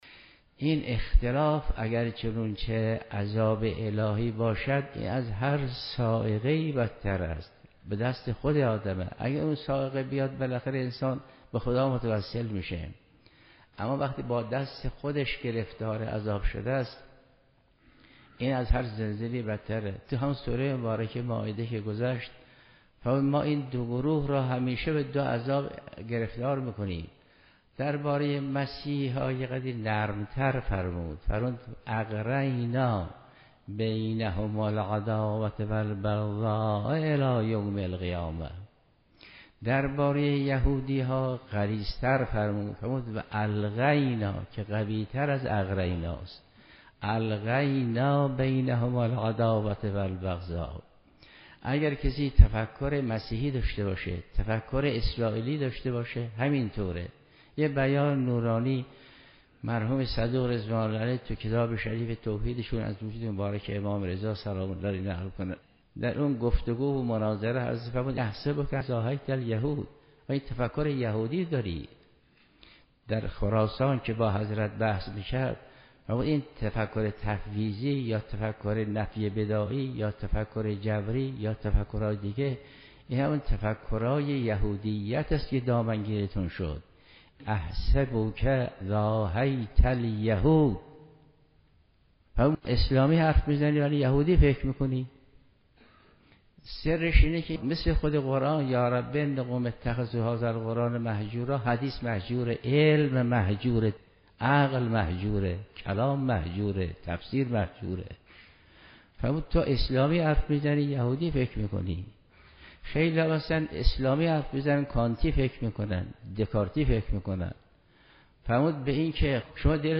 من يک وقت رفتم کنار قبر بعضي از اين شهدا حالا يا گمنام يا غير گمنام، شعري که برای فؤاد کرماني است ديدم بالاي قبر او با تعبیر دیگری نوشته شده است : گر بشکافند هنوز قبر شهيدان عشق ٭٭٭ آيد از آن کشتگان زمزمه يا حسين (برگرفته از درس تفسیر آیت الله جوادی آملی-27 دی 95)